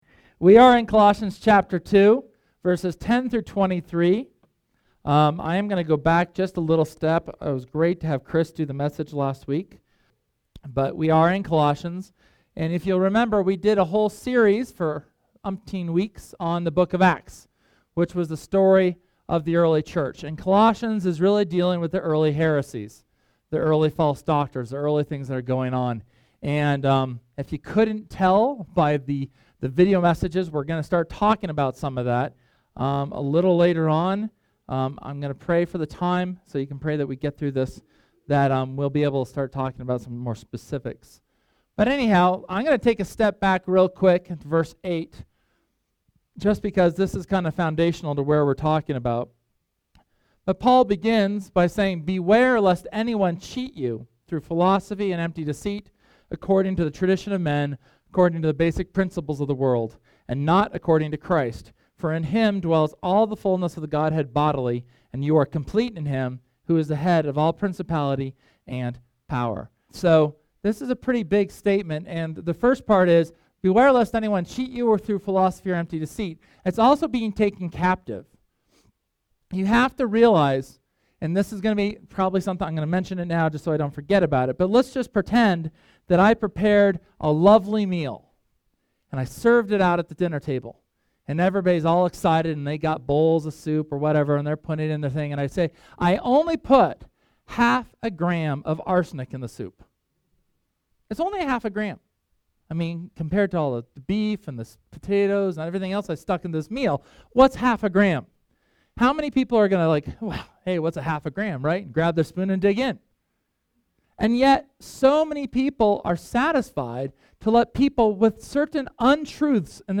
Sunday sermon from March 30th on Colossians chapter 2 verses 8-23.